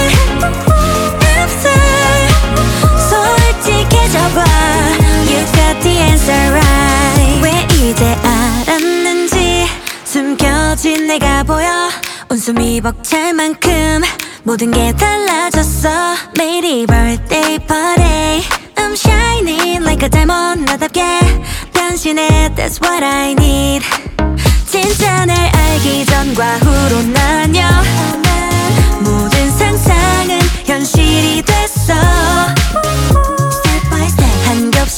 Dance Pop K-Pop
Жанр: Поп музыка / Танцевальные